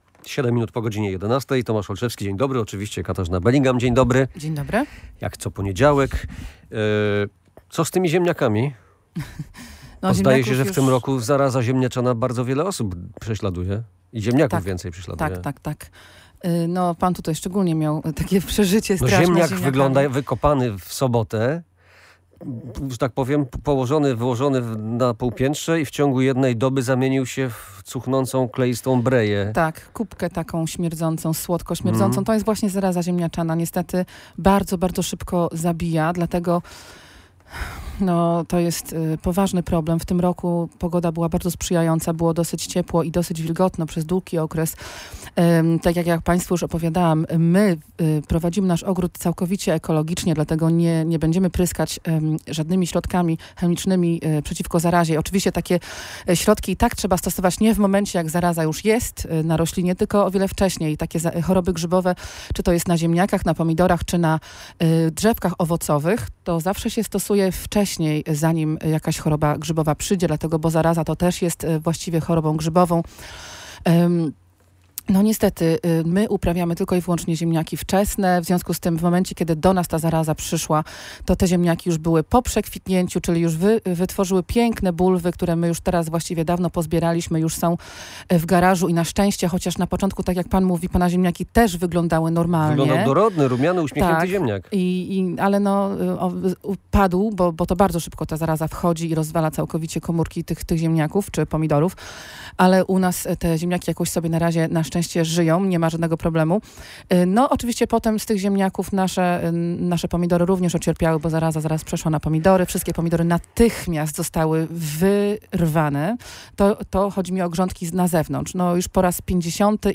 – Zaraza ziemniaczana bardzo szybko zabija. To poważny problem, a w tym roku warunki pogodowe bardzo sprzyjały: było ciepło i wilgotno. My prowadzimy swój ogród całkowicie ekologicznie, dlatego nie pryskamy roślin żadnymi środkami przeciw zarazie. Z resztą używać ich trzeba dużo wcześniej, nie dopiero kiedy coś zacznie się dziać – tłumaczyła ogrodniczka.